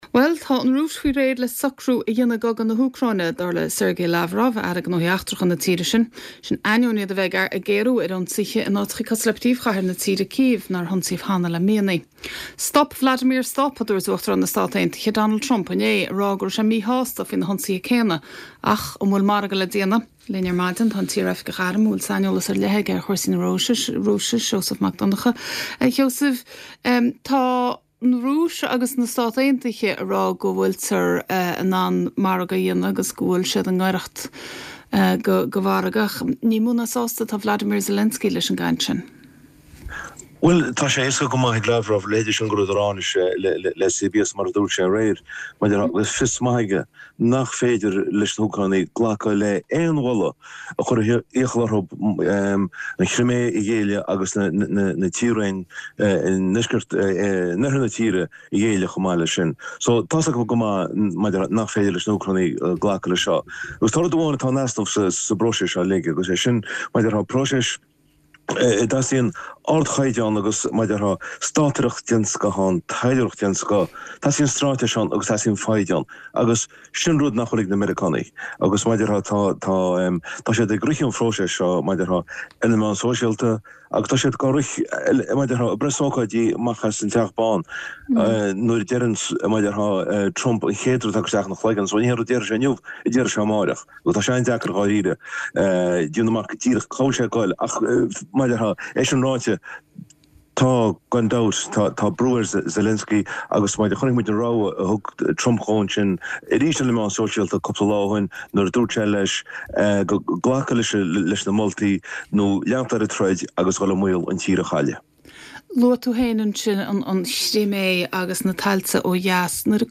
An Tuairisc Spóirt.